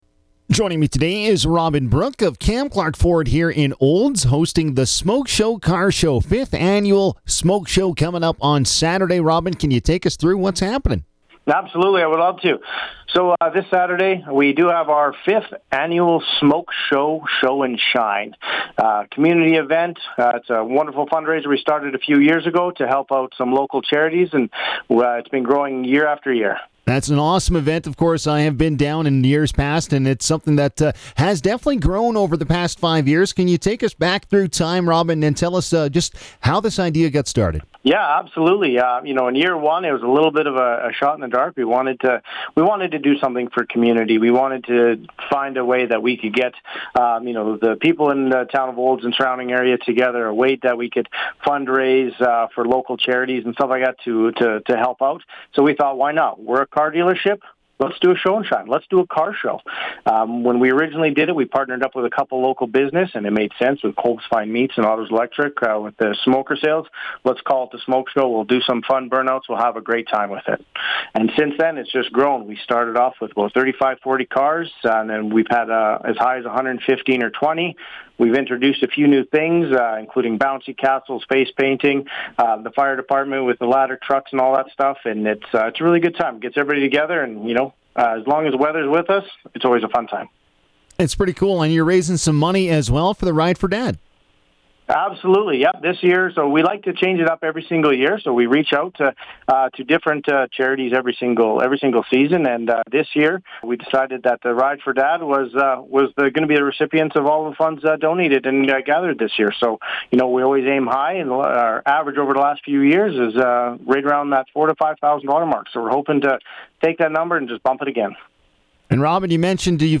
in conversation with 96.5 The Ranch